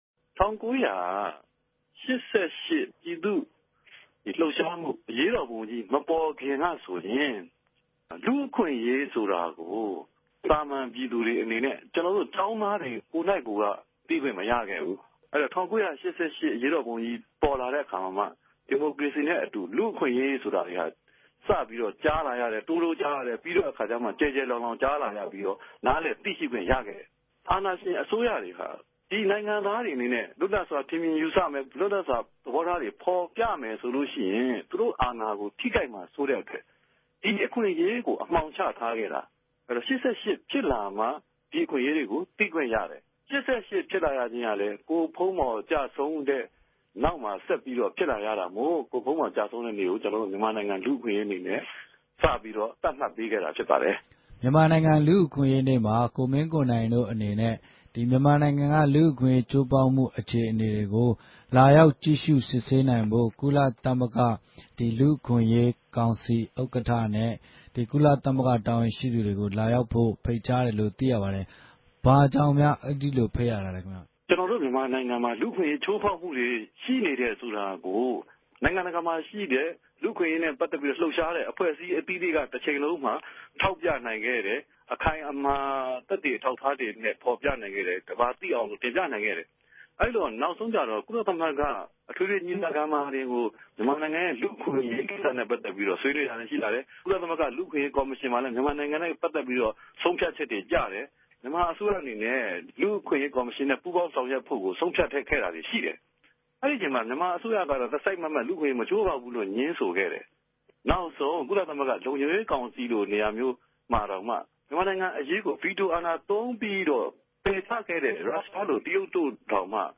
ဒီမတ်လ ၁၃ ရက်နေႛကို ူမန်မာိံိုင်ငံရဲ့ လူႛအခြင့်အရေးနေႛအူဖစ် သတ်မြတ်ရတဲ့အေုကာင်း မေးူမန်းတာနဲႛ ပတ်သက်လိုႛ ကိုမင်းကိိုံိုင်က စတင်ေူဖုကားခဲ့ပၝတယ်၊